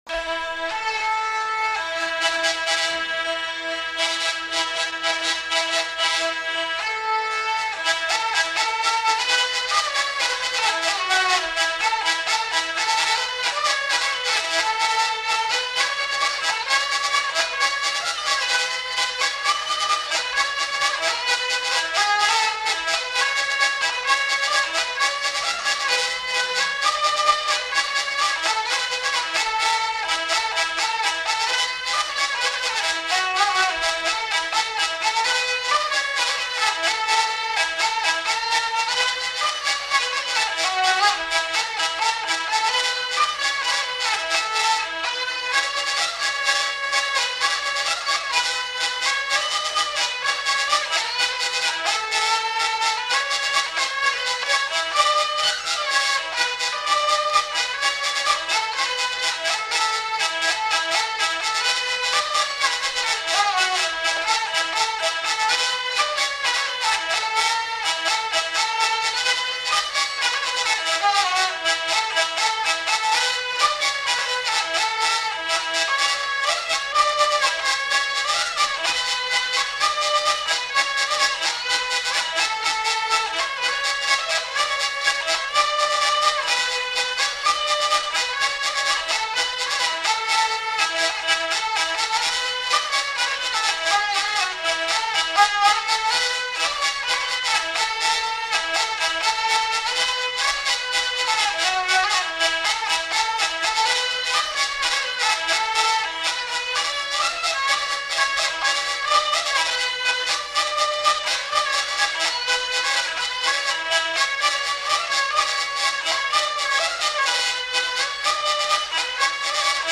Courante